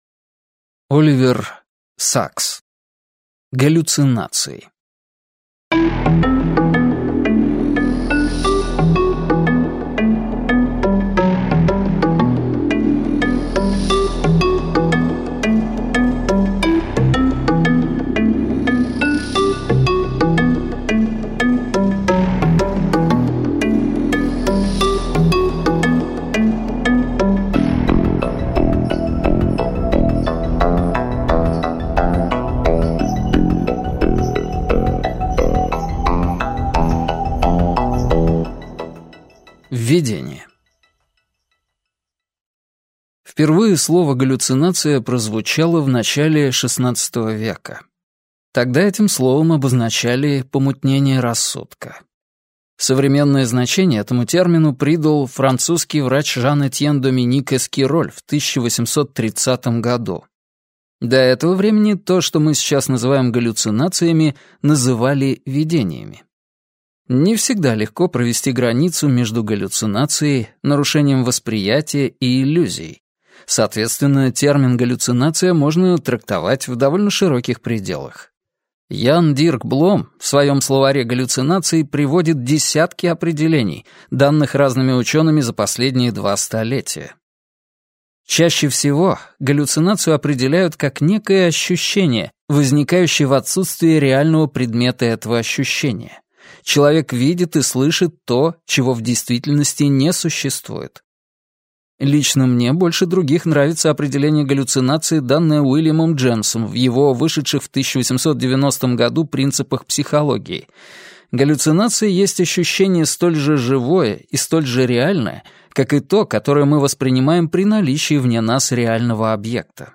Аудиокнига Галлюцинации - купить, скачать и слушать онлайн | КнигоПоиск